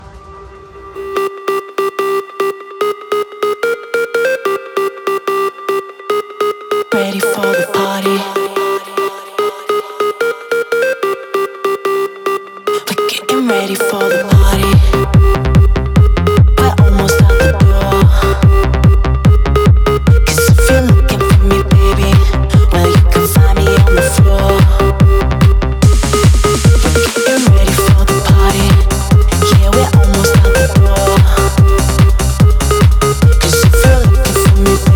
Жанр: Танцевальные / Транс